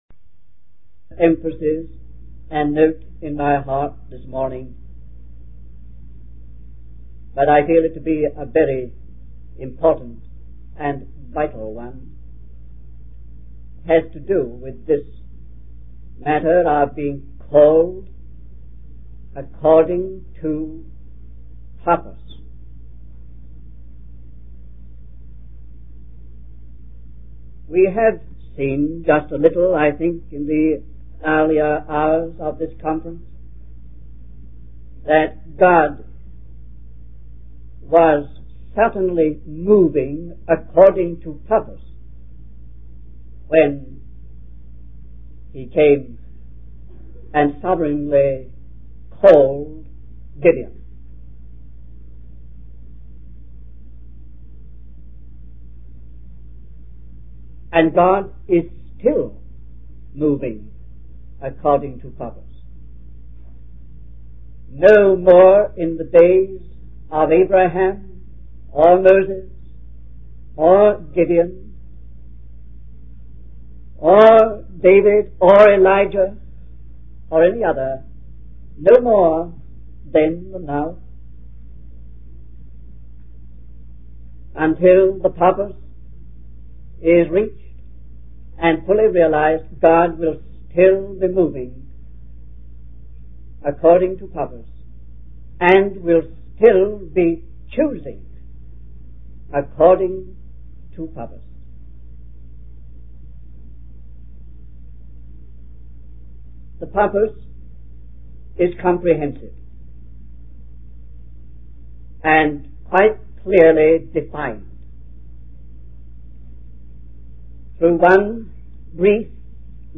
In this sermon, the speaker emphasizes the importance of being gripped and mastered by the fact that our lives are moving according to God's purpose.